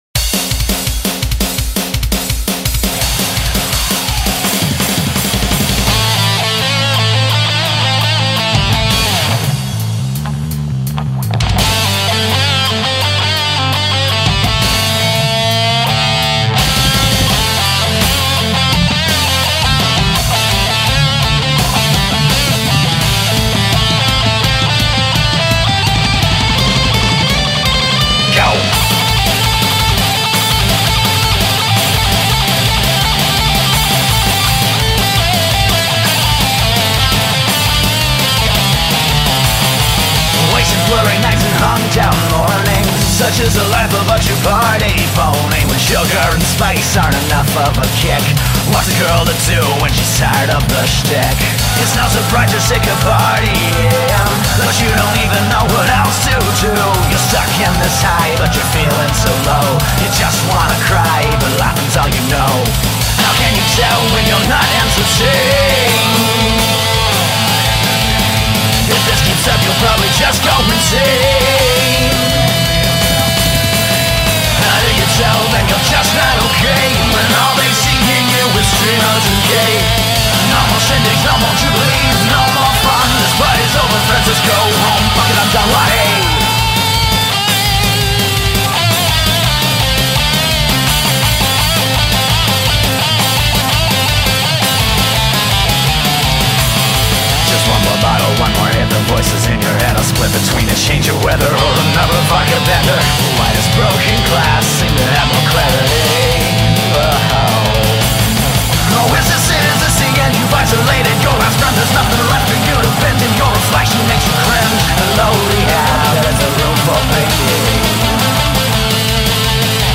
In-your-face pone music extrava-DANZA